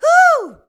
HUH.wav